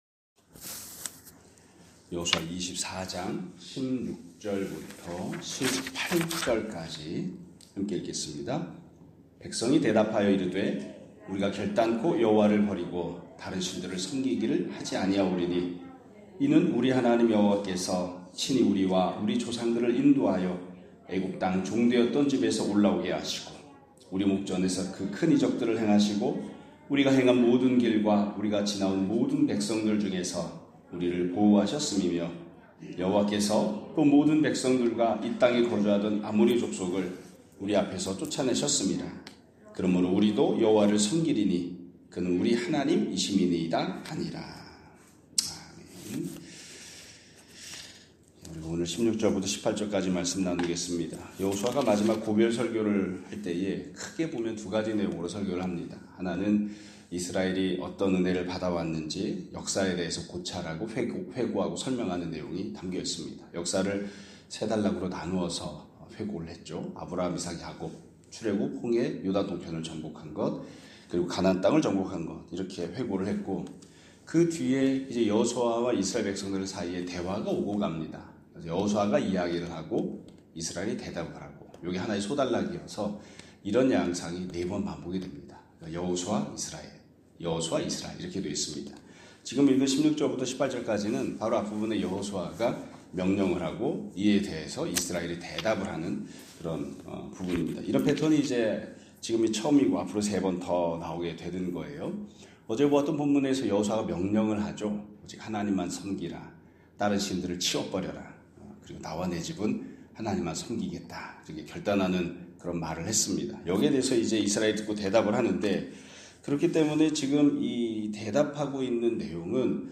2025년 2월 27일(목요일) <아침예배> 설교입니다.